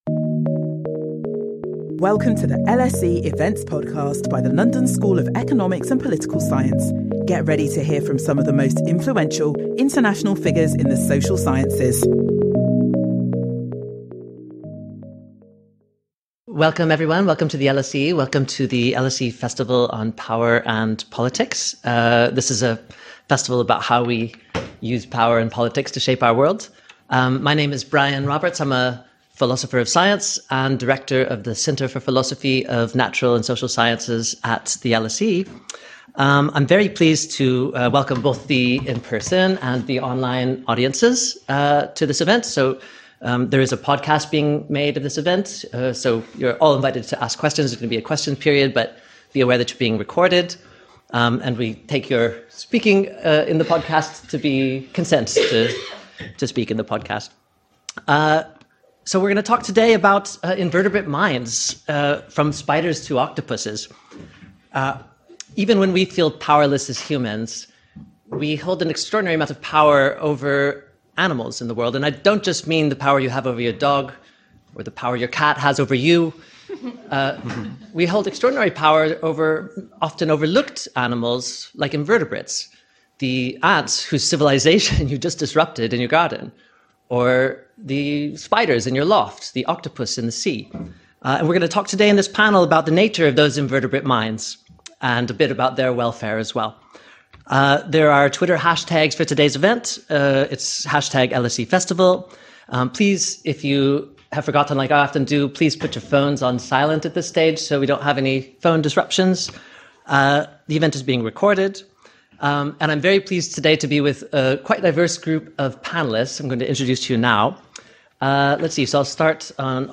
Our panel discusses how to include invertebrate animals from spiders to sea creatures in debates on animal welfare to ensure their interests are protected and promoted.